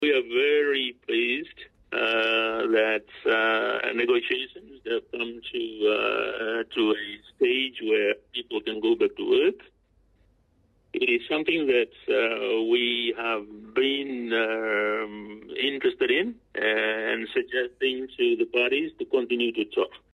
Civil Aviation Minister, Viliame Gavoka.